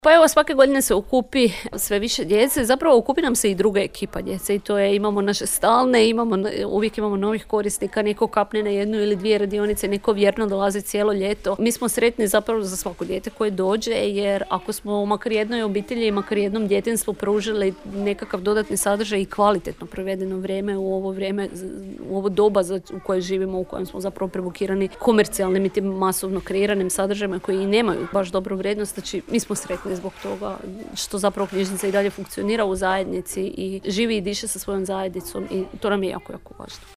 na radionici lego gradnje koju smo posjetili